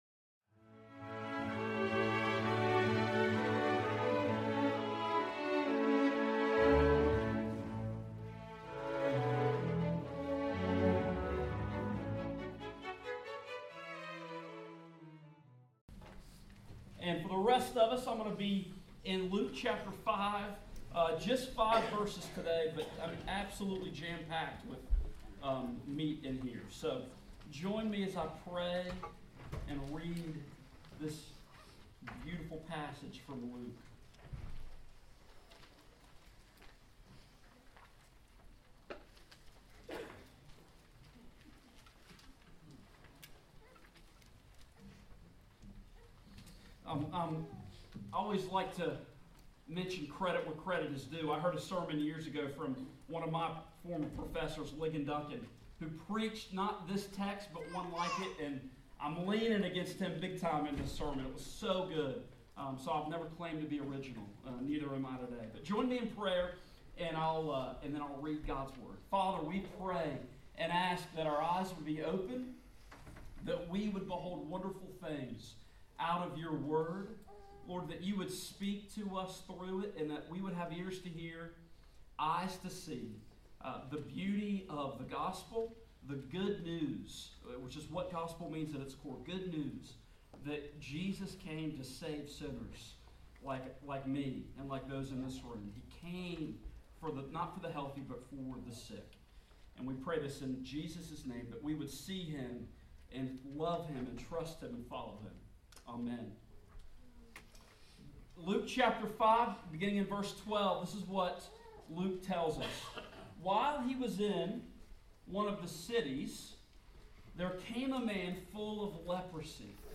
Luke 5:12-16 Service Type: Morning Service Title
Sermon-Intro_Joined.mp3